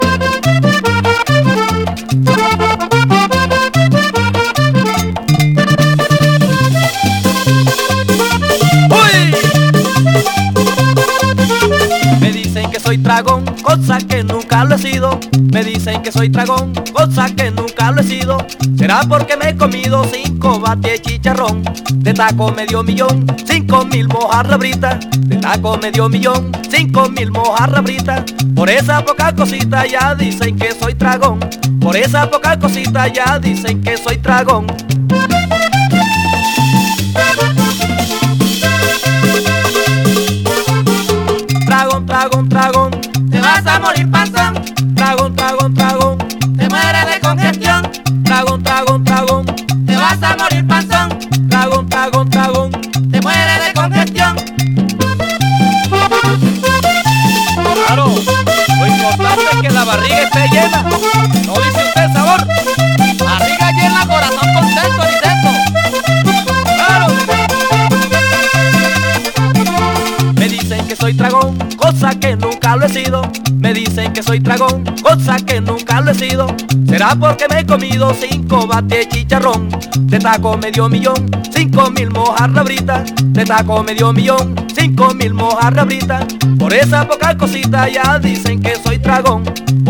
WORLD / LATIN / LATIN SOUL / LATIN FUNK / RARE GROOVE
キラーなラテン・ナンバー目白押し！
グルーヴィーなラテン・ナンバー満載の人気盤！